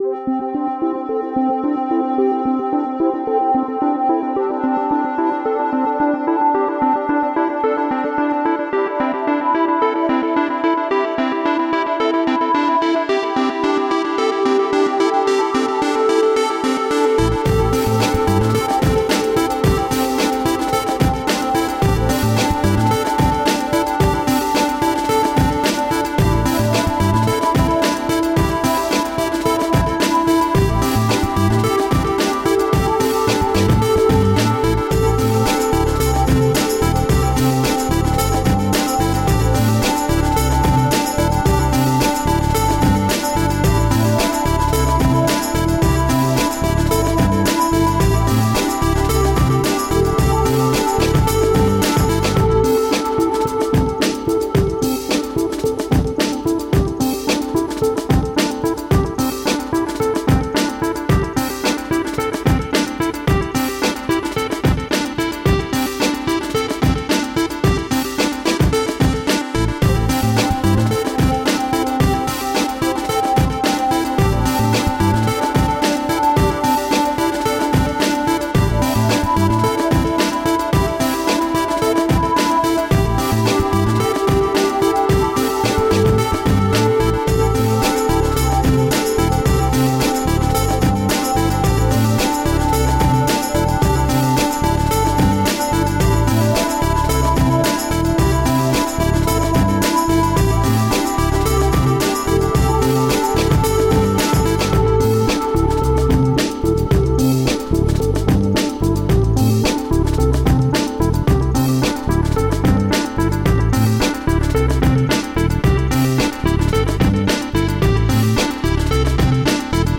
The most uplifting electronic music.
Tagged as: Electronica, Experimental, Chillout, Ethereal